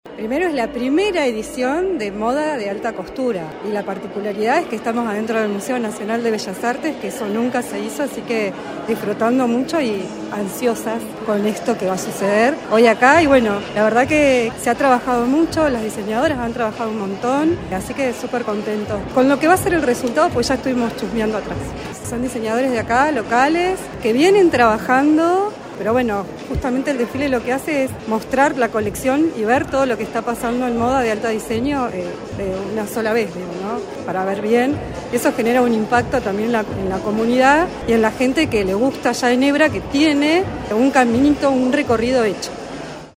Cintia Rojas, subsecretaria de Cultura.
Cintia-Rojas-EDITADO-Enhebra.mp3